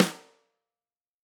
Index of /musicradar/Snares/Tama Wood
CYCdh_TamSnr-08.wav